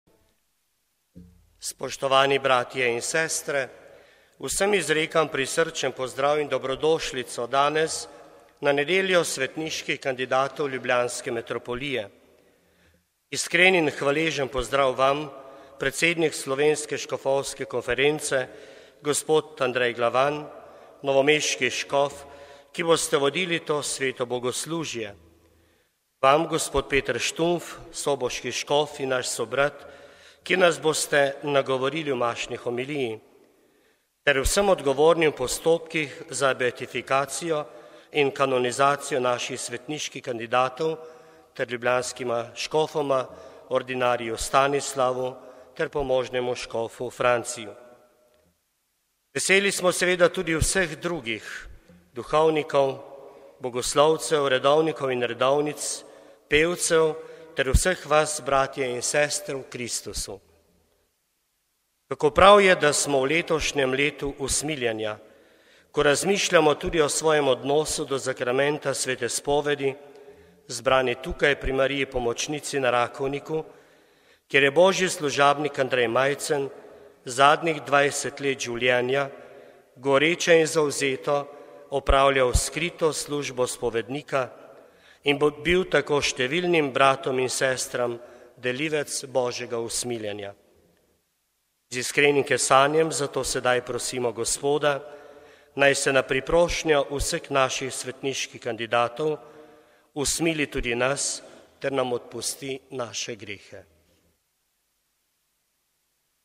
Na Rakovniku osrednja slovesnost nedelje svetniških kandidatov ljubljanske metropolije